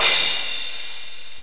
.7Smash_Cy.mp3